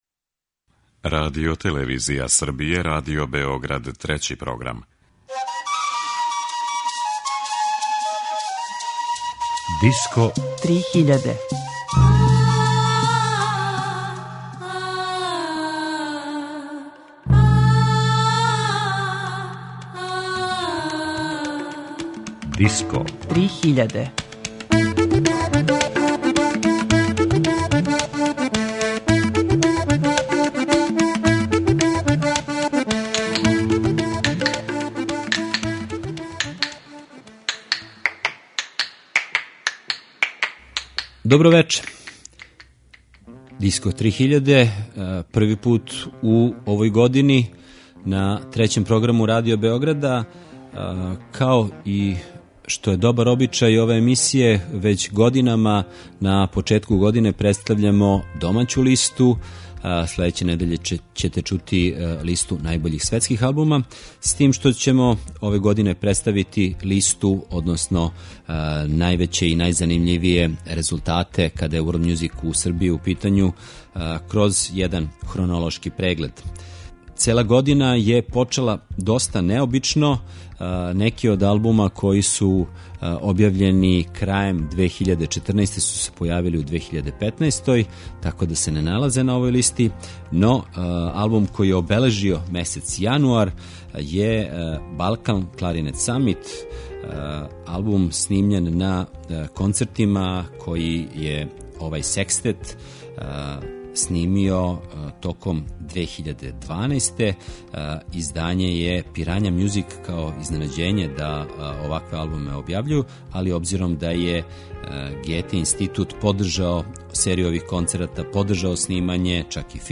Пуно одличне музике, најбоље од домаћих аутора у 2015.